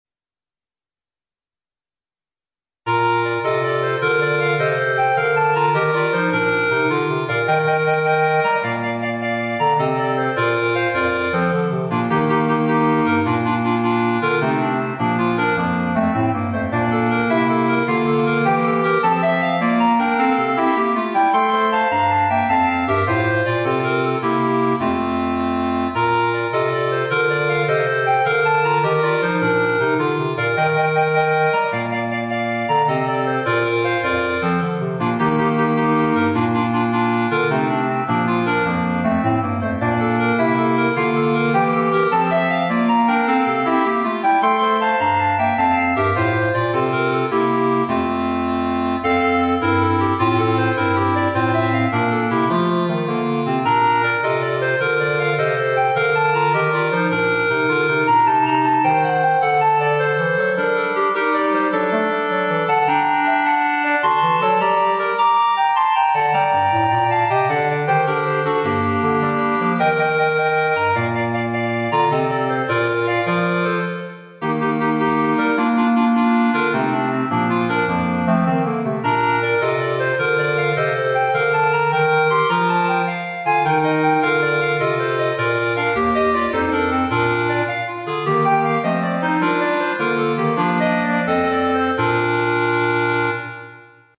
Gattung: für Klarinettenquartett
Besetzung: Instrumentalnoten für Klarinette